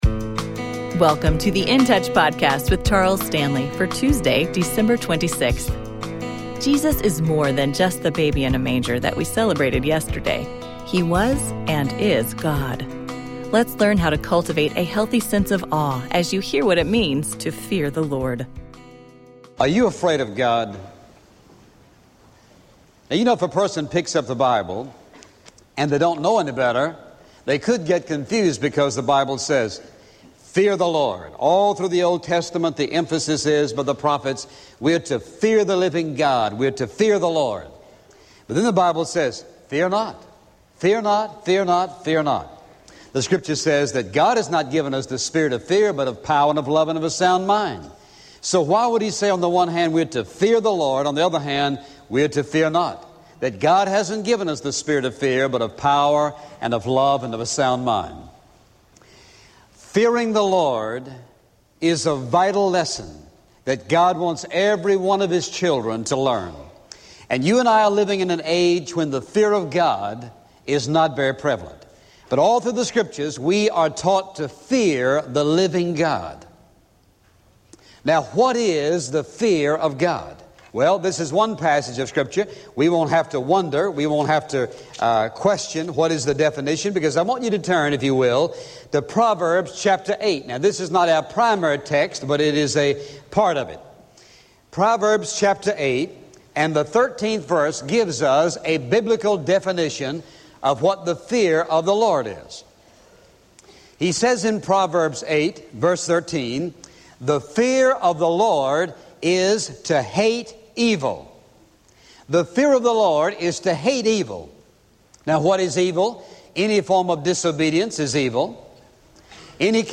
Daily Radio Program